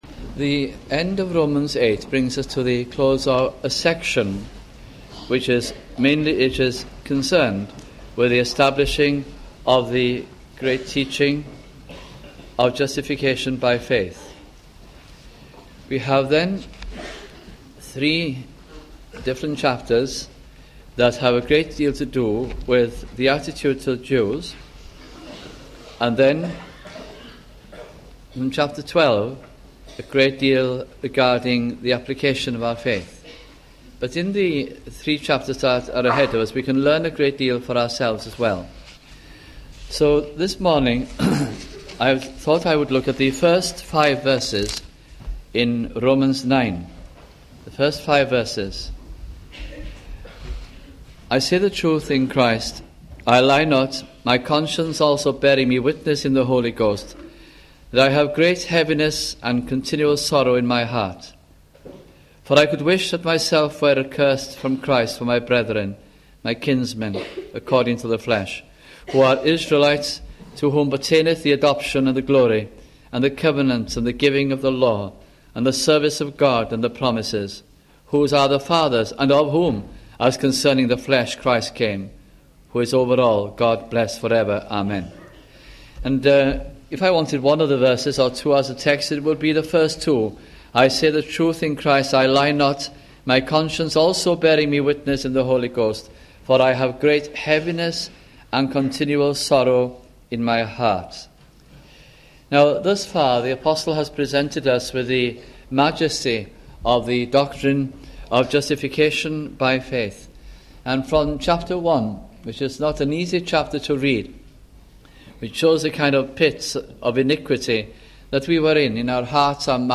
» Romans Series 1987 - 1988 » sunday morning messages